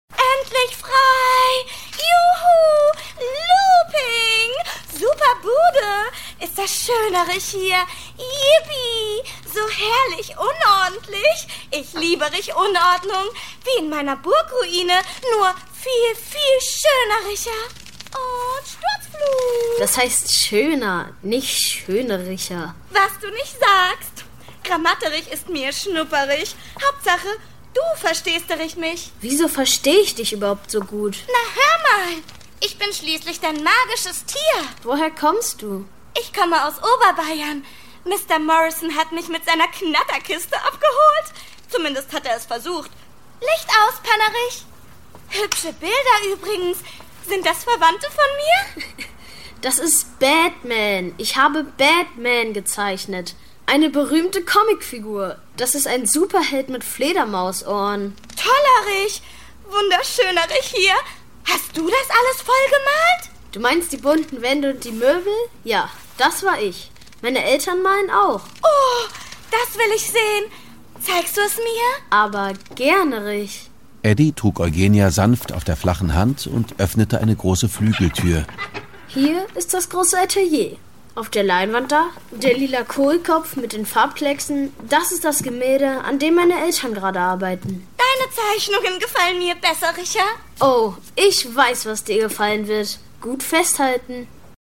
Sprecherin, Werbesprecherin, Schauspielerin